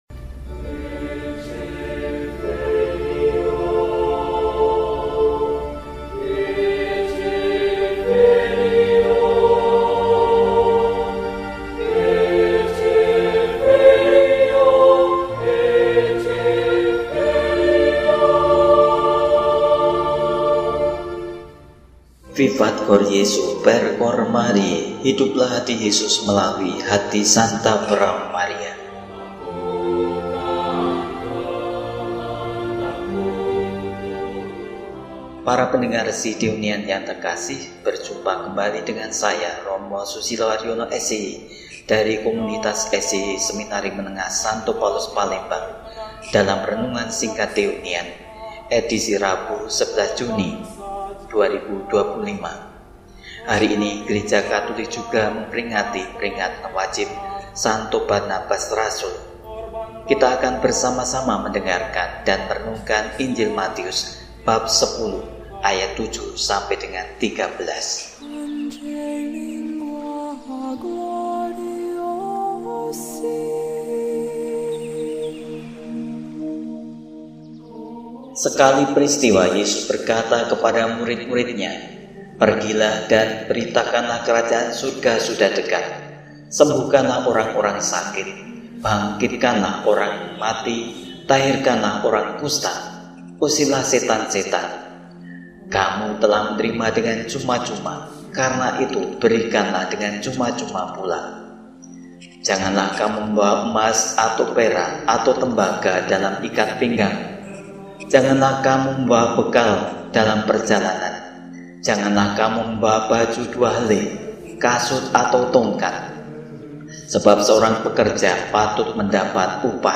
Rabu, 11 Juni 2025 – Peringatan Wajib St. Barnabas, Rasul – RESI (Renungan Singkat) DEHONIAN